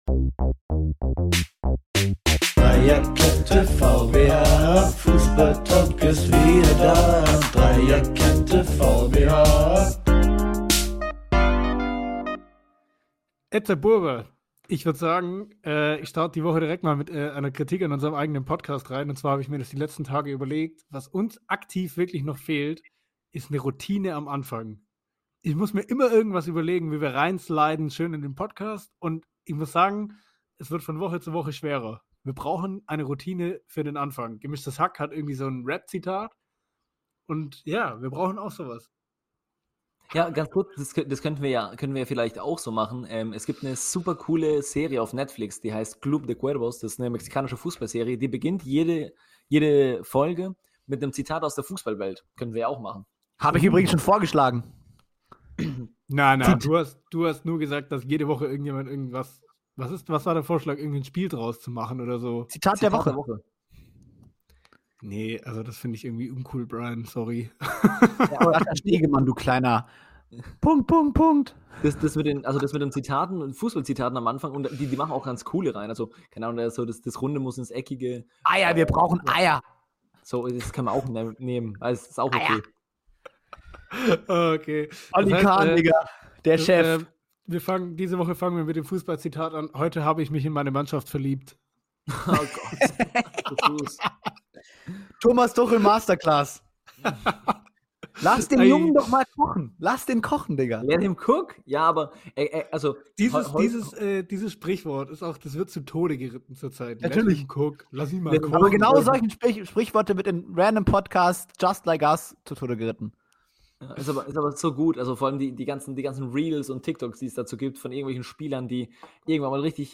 Eine erneute Aufnahme die zeigt, dass die räumliche Entfernung und sechs Stunden Zeitunterschied kein Hindernis für uns sind, eure Woche mit gefährlichem Halbwissen über die Welt des Fußballs zu versüßen.